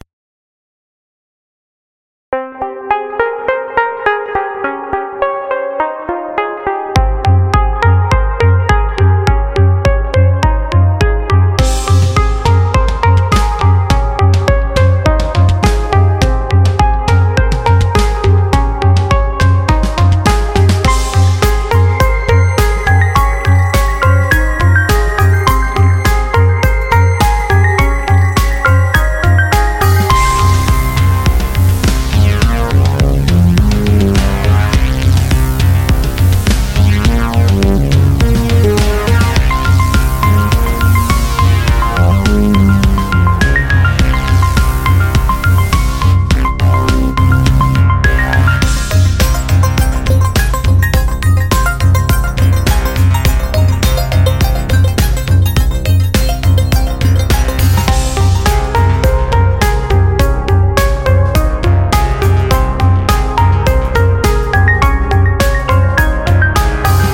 - электроника.